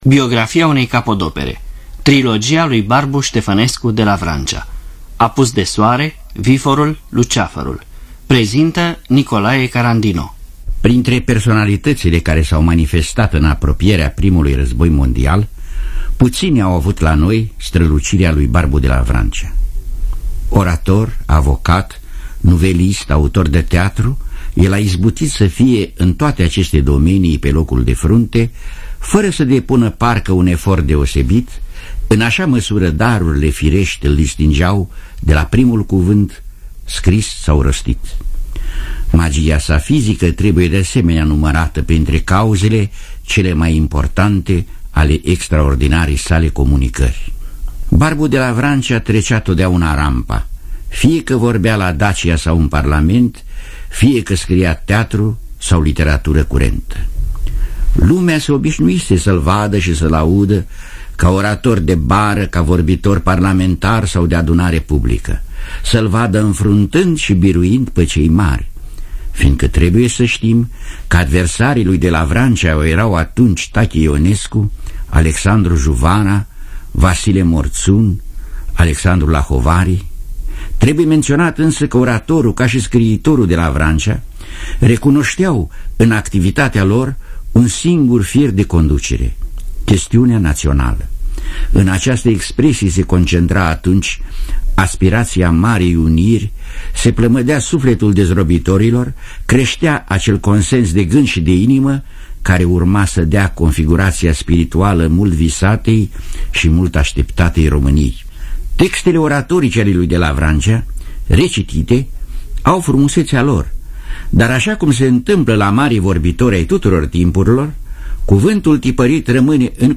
Cu fragmente din spectacolele păstrate în Fonoteca de aur a Teatrului Național Radiofonic.